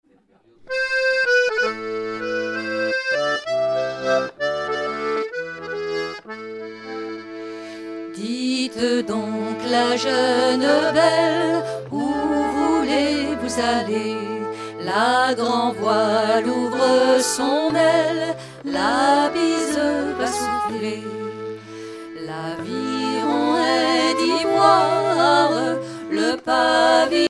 Genre strophique
Concert donné en 2004
Pièce musicale inédite